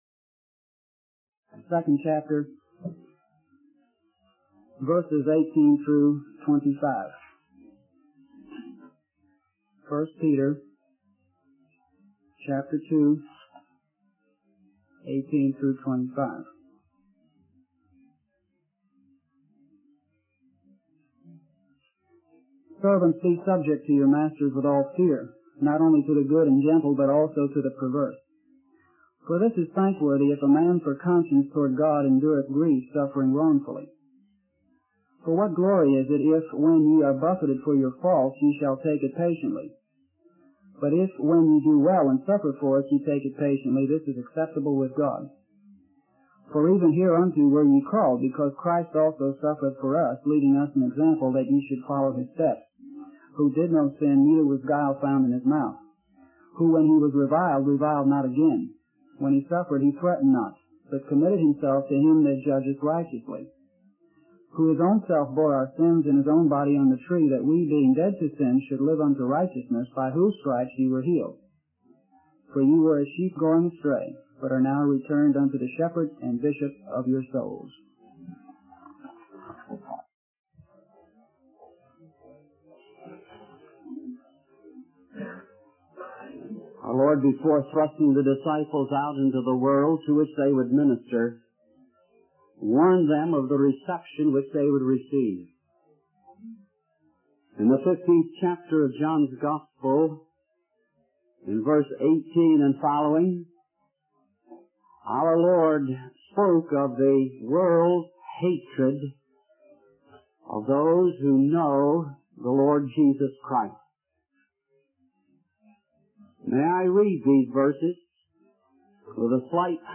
In this sermon, the speaker focuses on the importance of submission to authority, specifically in the context of the business world. The speaker emphasizes that believers who work in the business world are living in a hostile environment that is often opposed to the values and goals of Jesus Christ. The speaker highlights the need for believers to be subject to their employers and to live with a clear conscience before God.